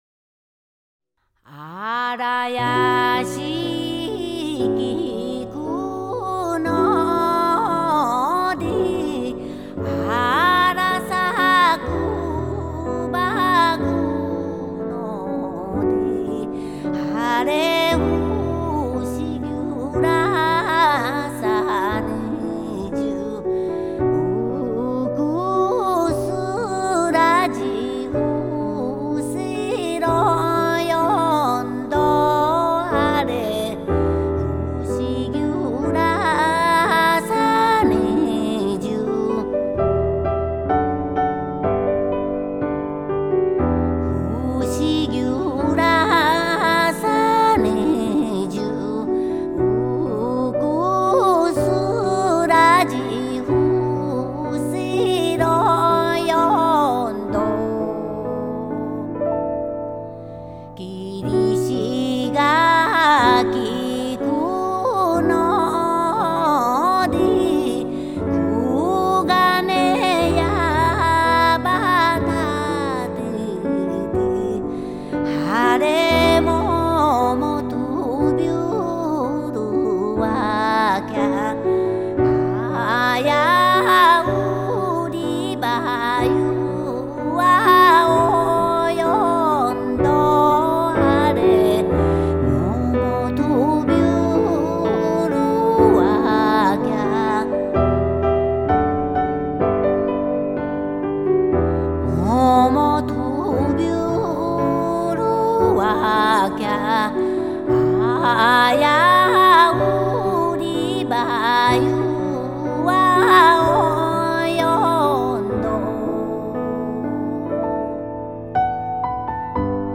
à écouter au casque, le magnifique chant